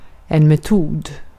Ääntäminen
US RP : IPA : /məʊd/ GenAm: IPA : /moʊd/